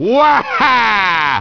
Mario Kart DS Sounds